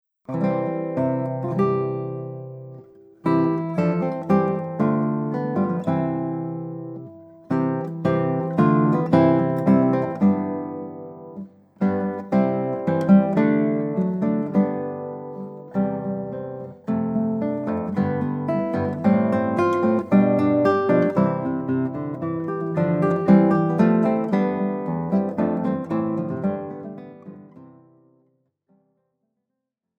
Sechs Jahrhunderte Gitarrenmusik für Gitarrenduo
Besetzung: 2 Gitarren
RENAISSANCE